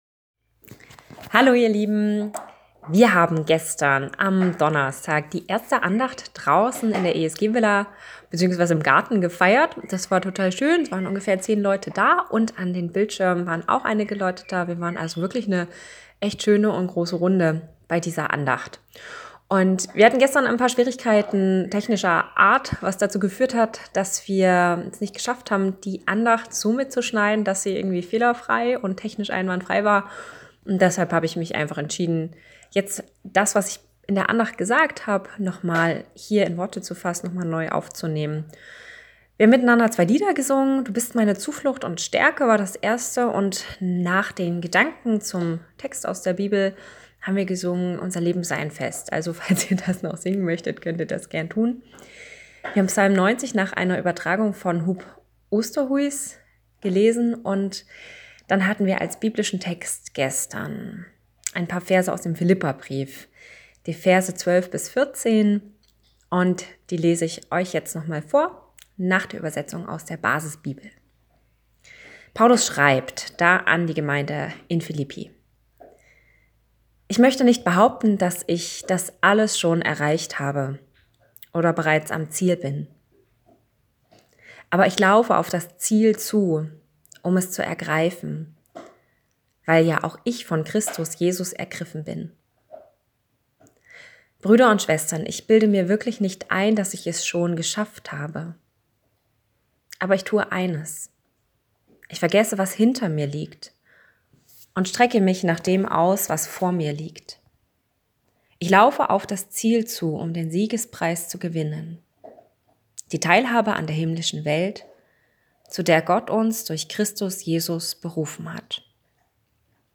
Morgenandacht am 14. Mai 2020 | Evangelische Studierendengemeinde Dresden